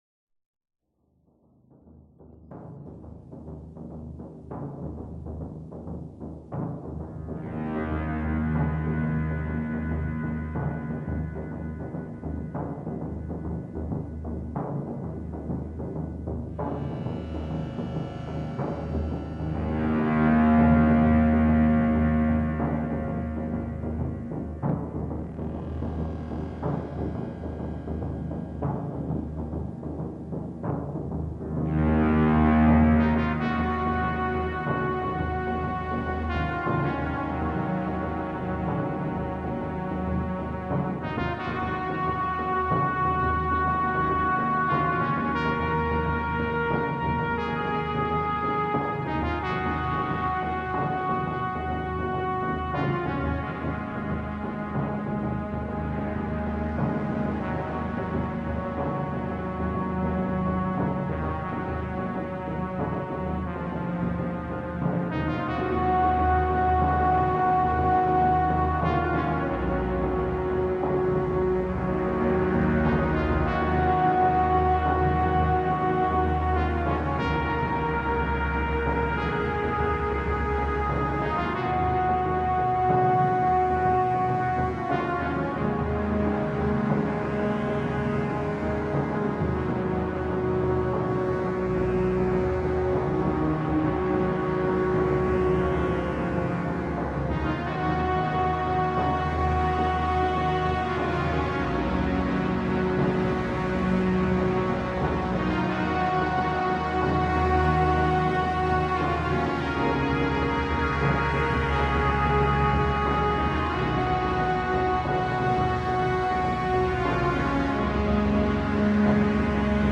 Soundtrack, Sci-Fi